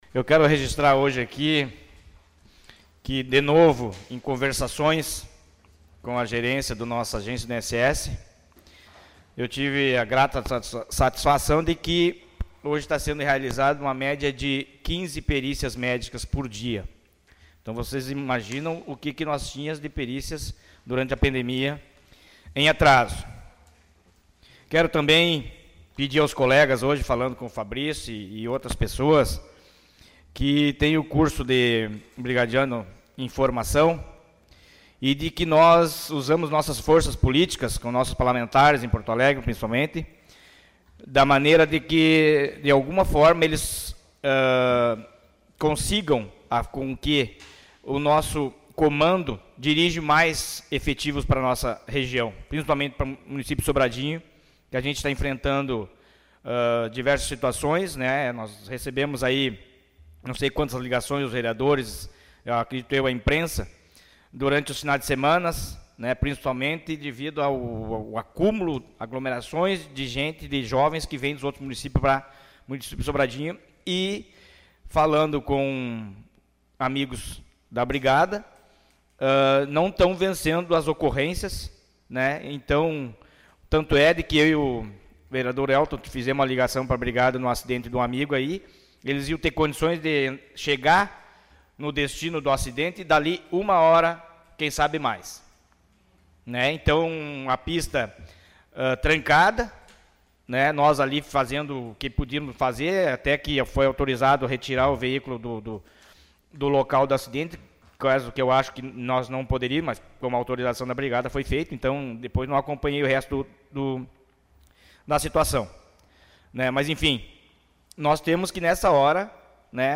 Os Vereadores de Sobradinho estiveram reunidos nesta segunda-feira (09), na 30ª Sessão Ordinária de 2021.
Confira o pronunciamento feito na tribuna pelo vereador Valdecir Bilhan (PTB):